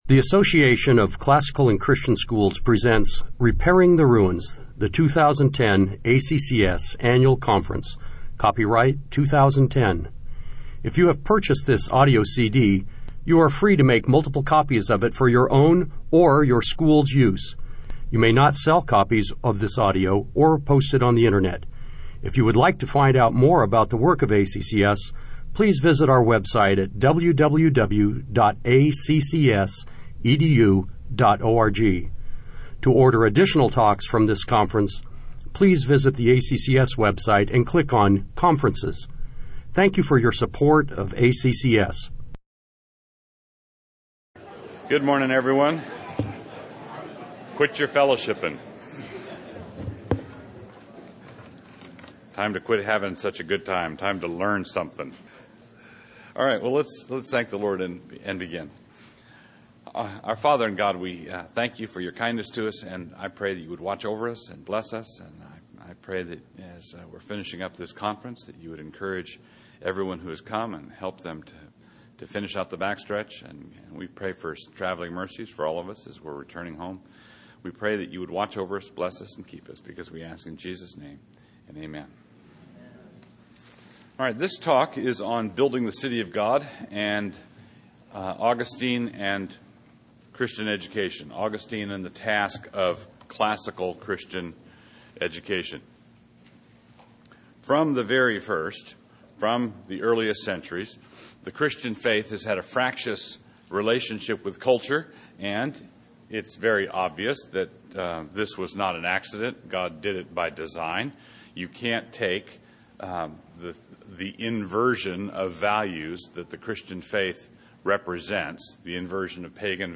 2010 Workshop Talk | 1:02:59 | All Grade Levels